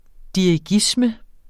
Udtale [ diɐ̯iˈgismə ]